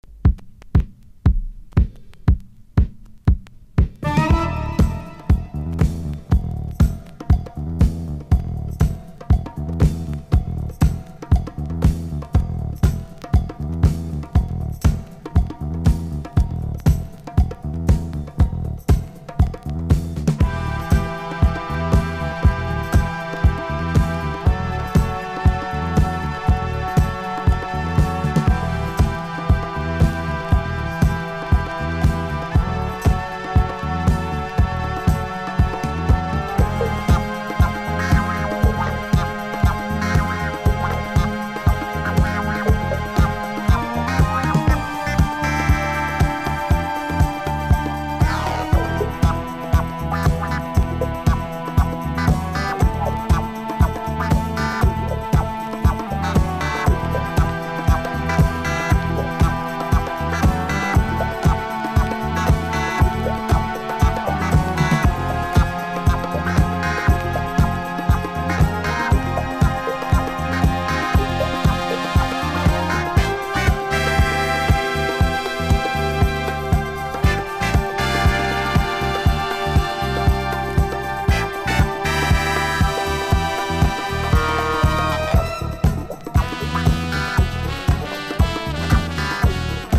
ワウの効いたシンセ・ギターが印象的な